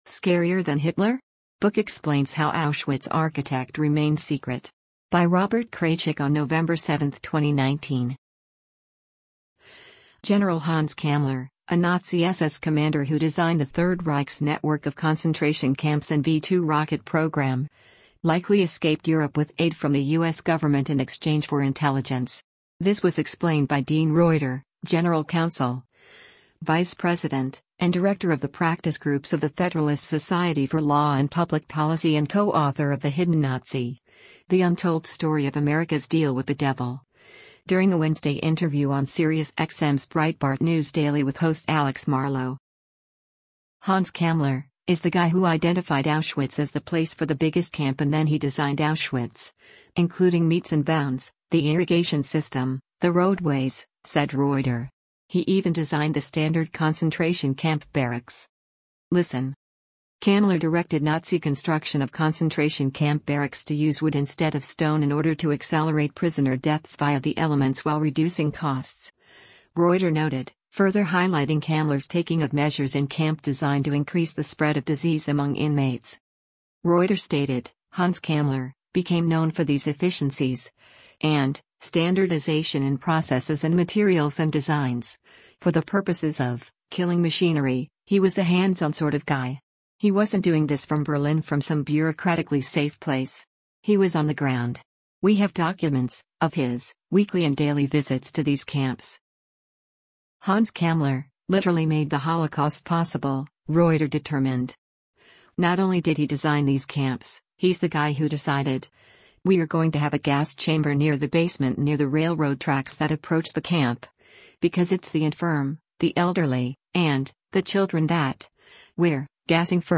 Breitbart News Daily broadcasts live on SiriusXM Patriot 125 weekdays from 6:00 a.m. to 9:00 a.m. Eastern.